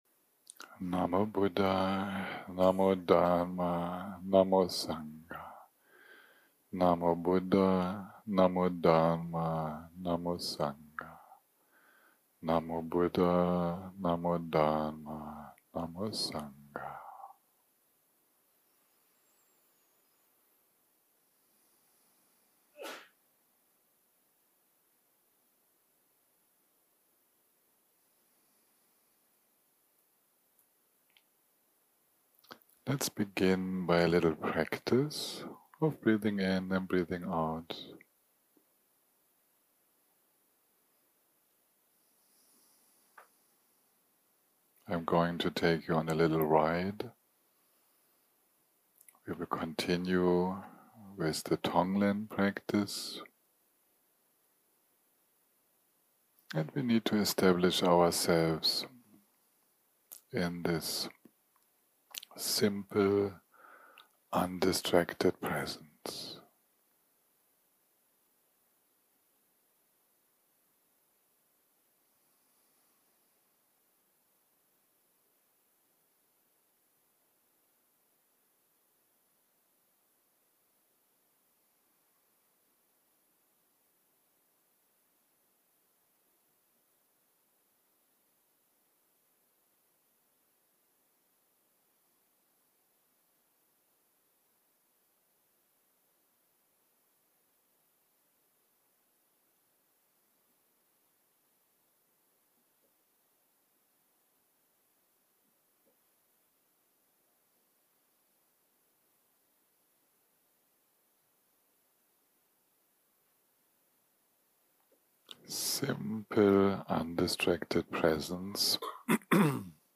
שיחת הנחיות למדיטציה שפת ההקלטה